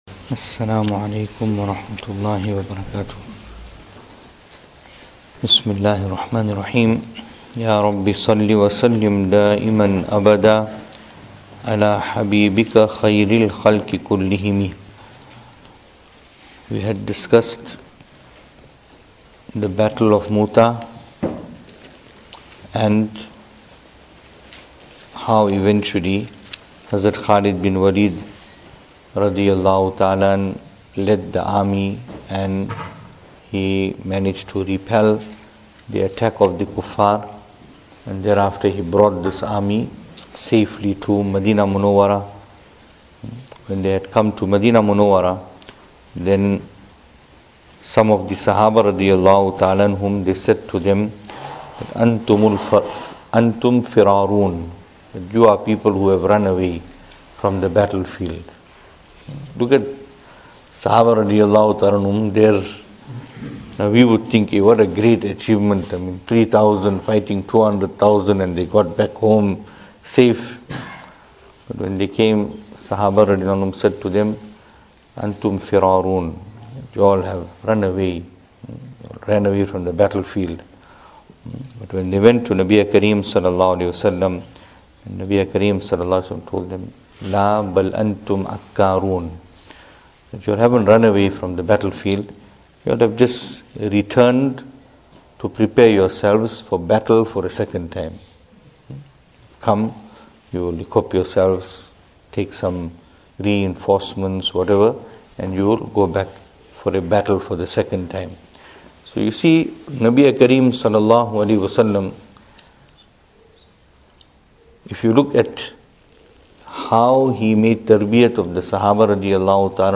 Venue: Masjid Taqwa, Pietermaritzburg | Series: Seerah Of Nabi (S.A.W)